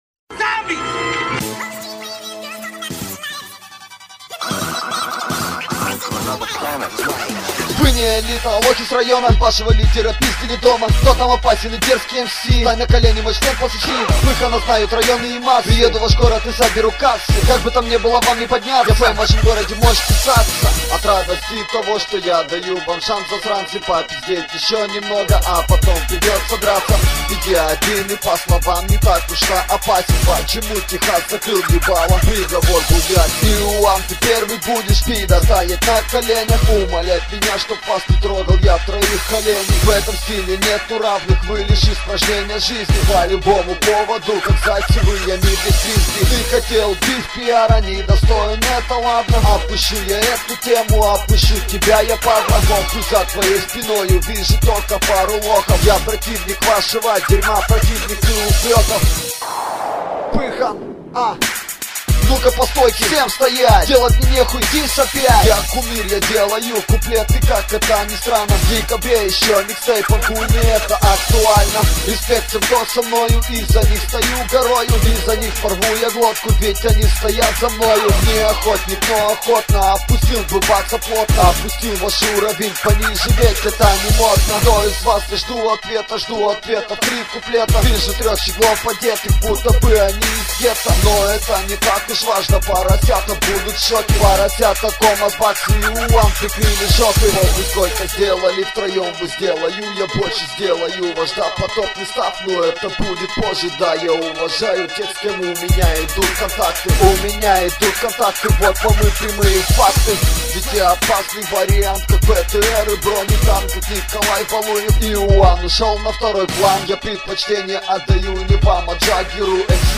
Категория: DiSS